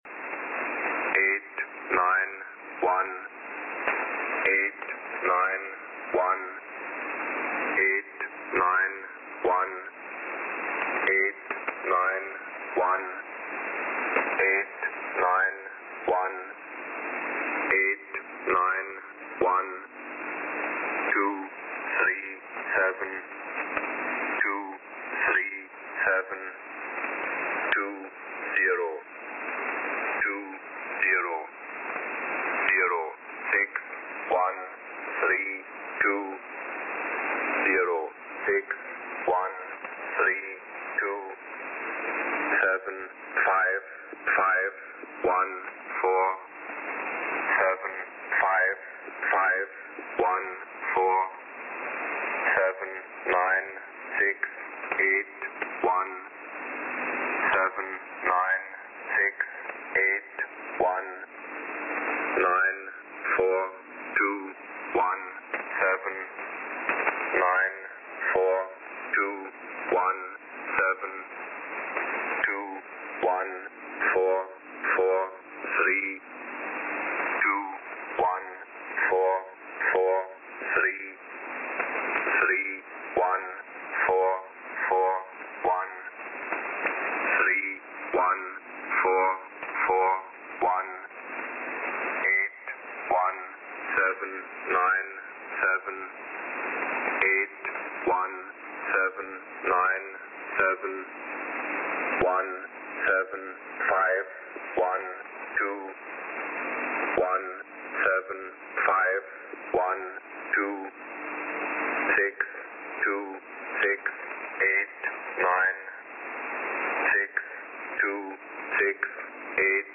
E06 is a Russian numbers station that has been active since the 1970’s.  It has changed transmission modes and voices many times since then.  The audio for E06 is fed through windows XP and so there are occasionally sounds heard from the operating system.
About 10 minutes before a message, a test tone followed by the ID of the station is transmitted.
Emission Mode: USB + Carrier, USB (formerly AM)
Voice Summary: Automated male voice, had many voice changes during its existence. Current voice is a very low, slightly accented baritone.